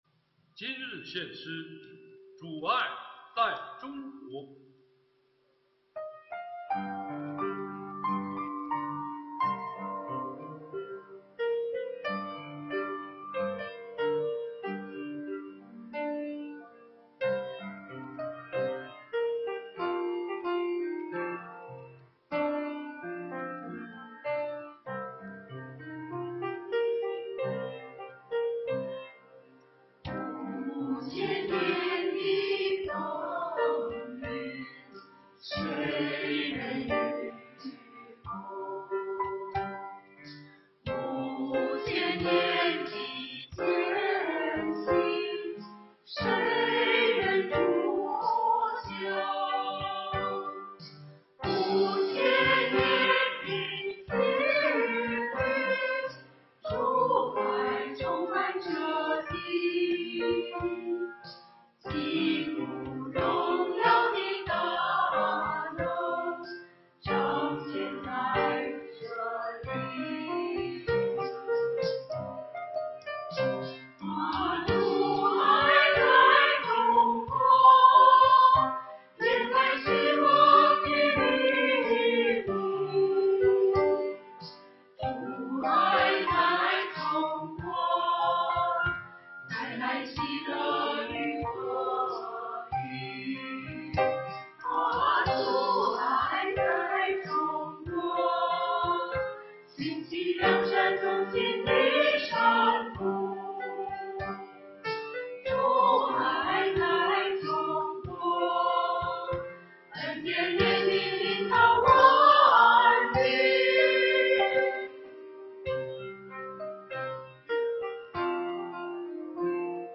团契名称: 联合诗班 新闻分类: 诗班献诗 音频: 下载证道音频 (如果无法下载请右键点击链接选择"另存为") 视频: 下载此视频 (如果无法下载请右键点击链接选择"另存为")